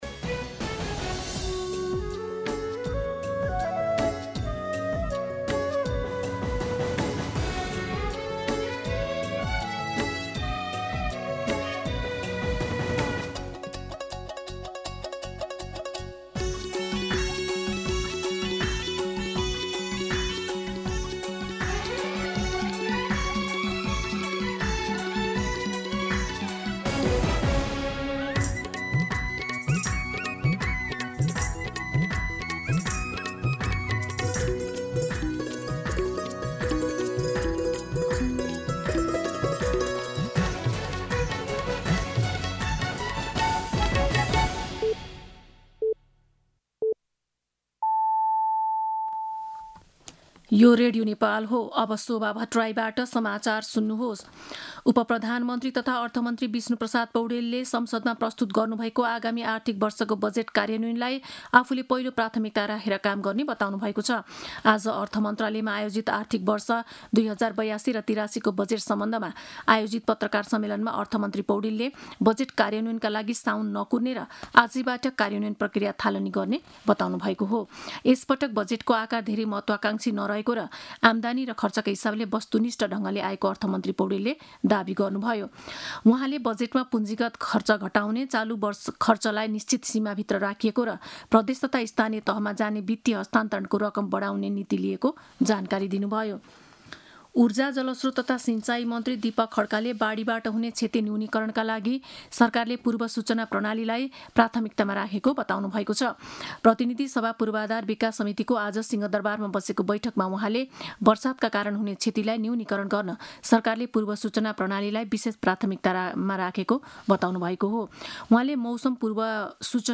दिउँसो ४ बजेको नेपाली समाचार : १६ जेठ , २०८२
4pm-News-02-16.mp3